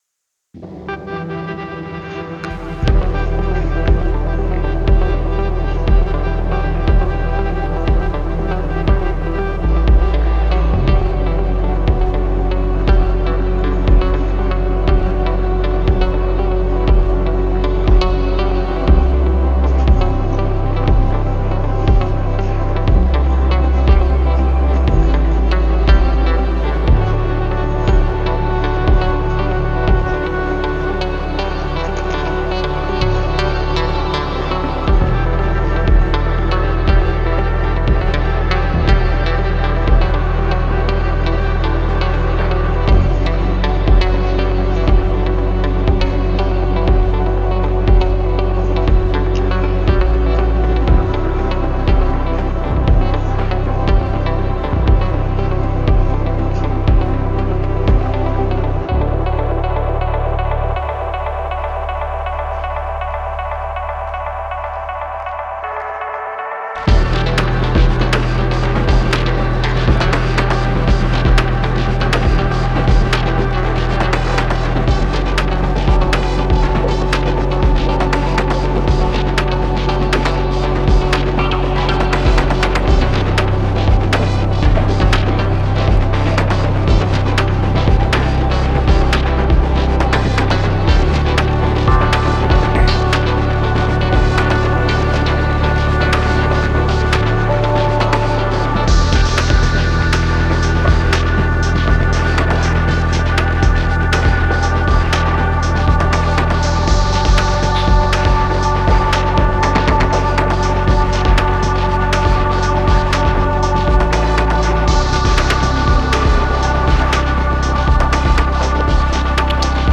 An electronic audio time-lapse of abstract journeys.